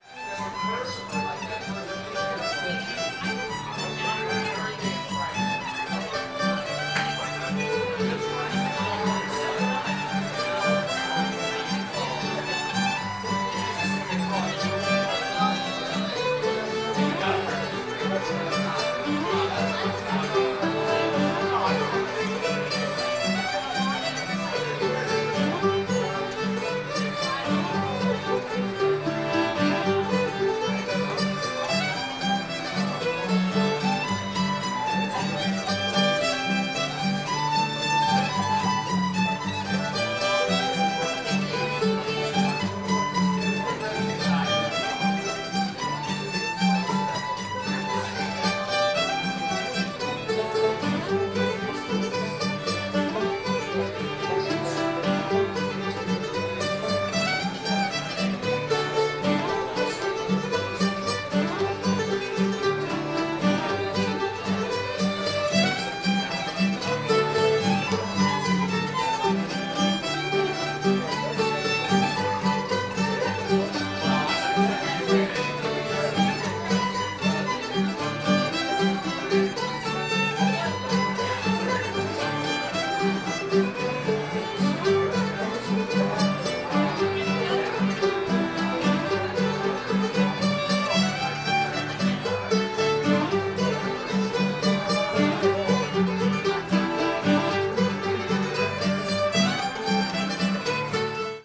springfield girl [G]